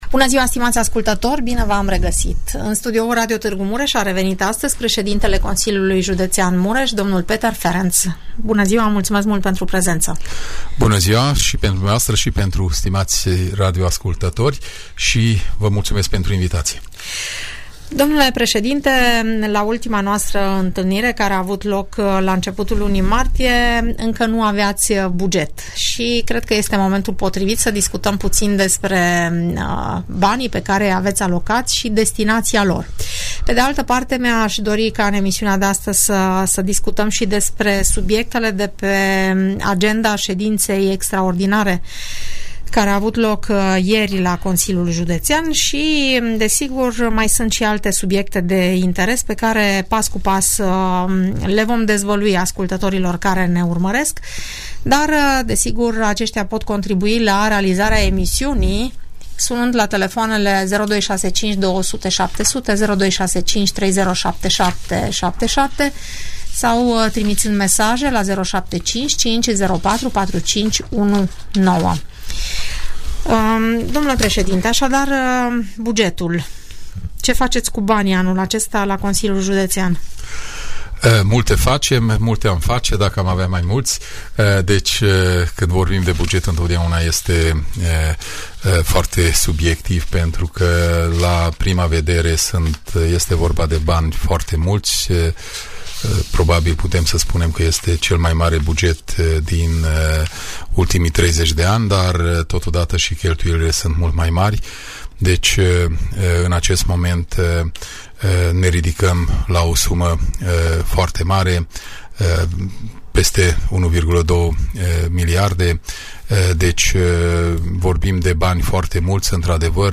» Despre activitatea Consiliului Județean Mureș Despre activitatea Consiliului Județean Mureș Peter Ferenc, președintele Consiliului Județean Mureș, explică în emisiunea "Părerea ta", care sunt proiectele în curs de finalizare, dar și cele care urmează să fie demarate la nivel de județ.